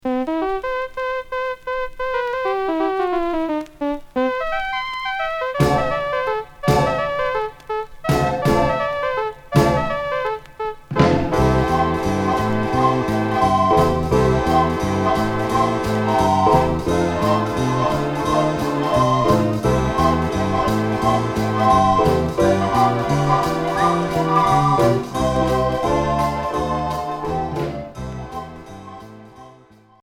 Orchestre enfantin